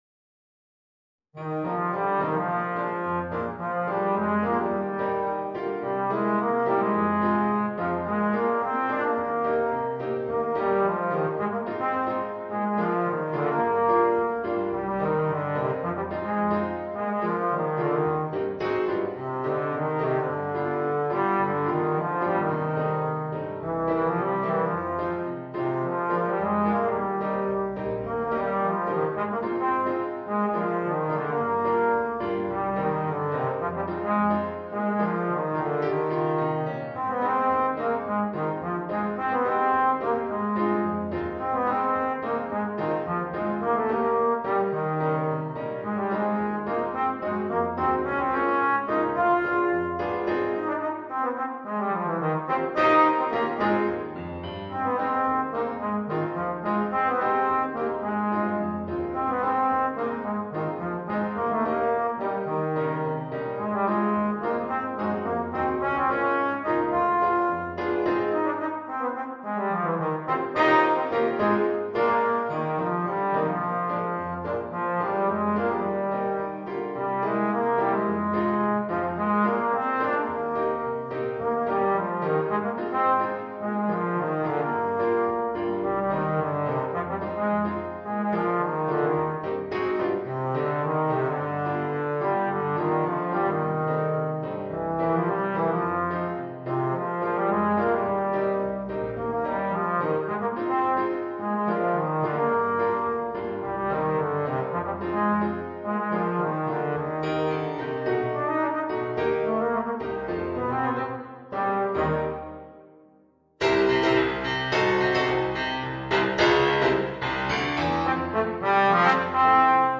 Voicing: Trombone and Piano or CD